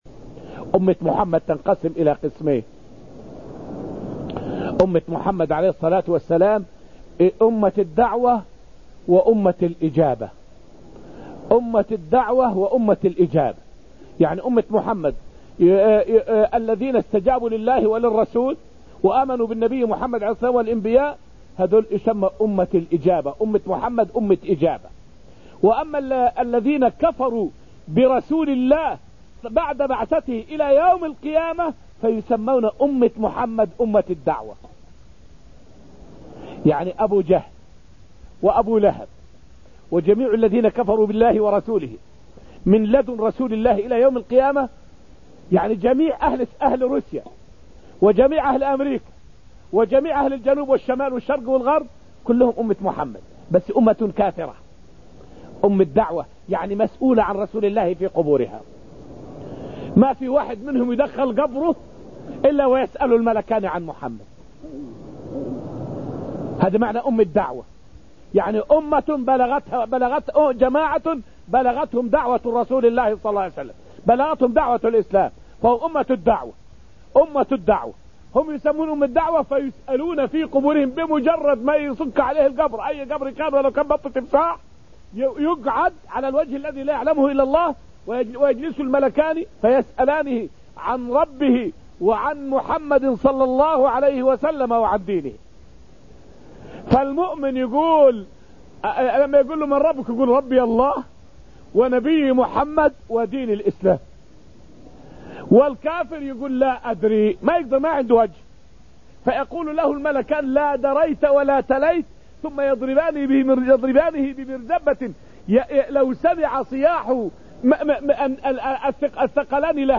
فائدة من الدرس العشرون من دروس تفسير سورة الحديد والتي ألقيت في المسجد النبوي الشريف حول أمة الدعوة وأمة الإجابة.